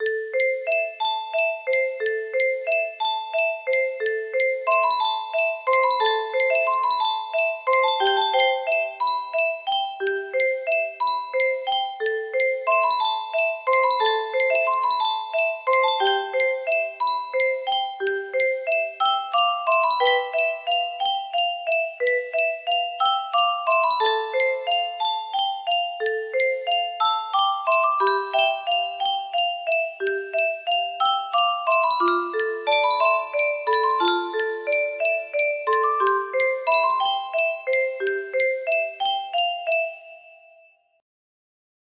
Regalo Kimsan music box melody